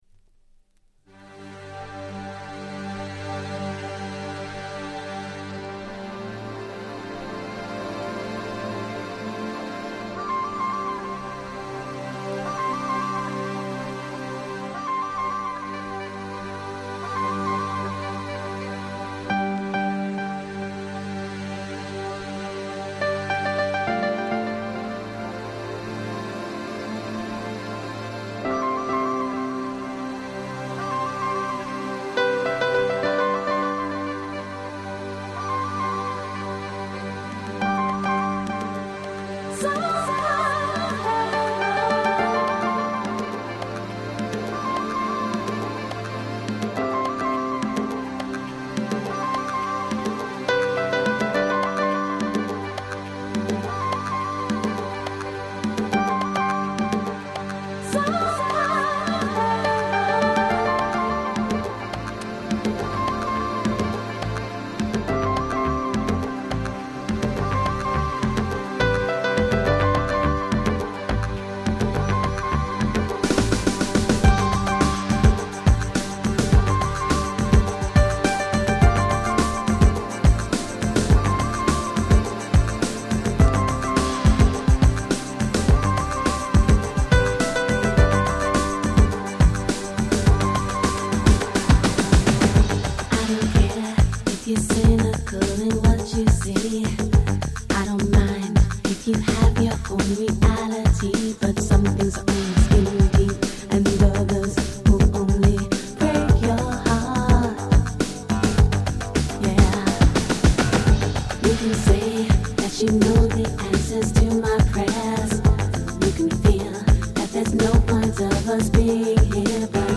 90' Nice Ground Beat !!
ジャケットに違わない素敵な曲調のNiceな1枚です！